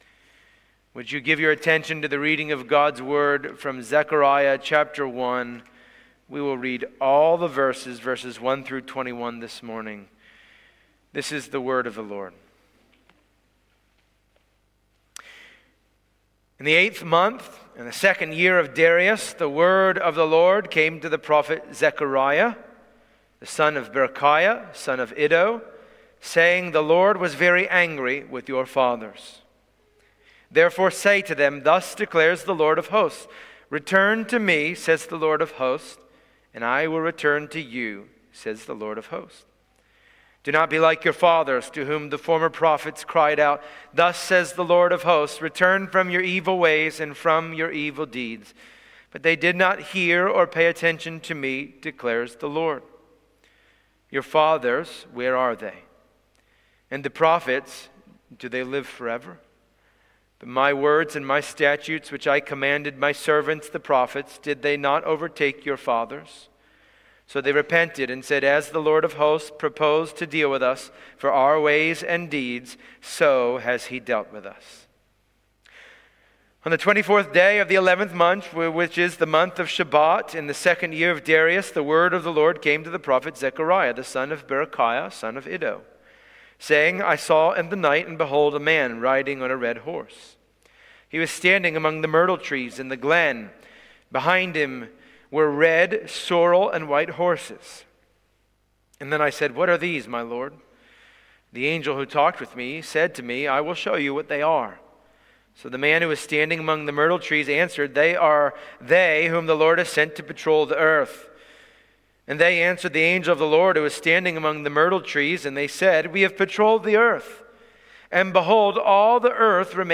Passage: Zechariah 1:1-21 Service Type: Sunday Morning